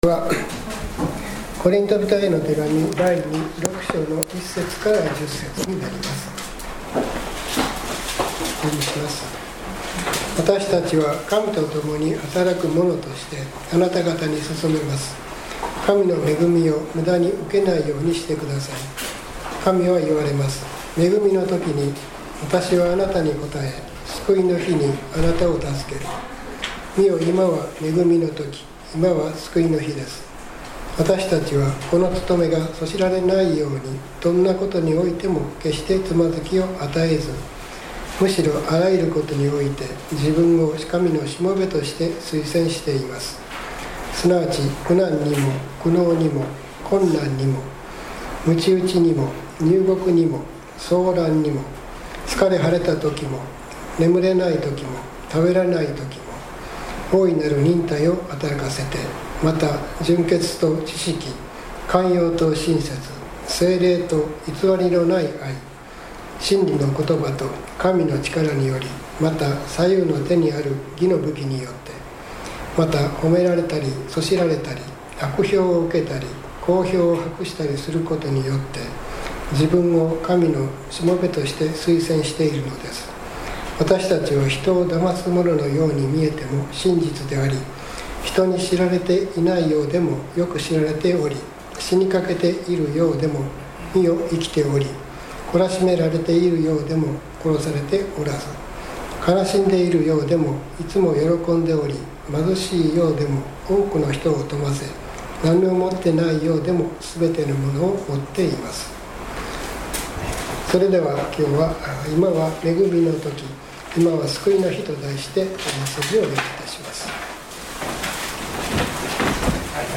３月１日の礼拝メッセージ「今は恵みの時、今は救いの日です」Ⅱコリント６：１－１０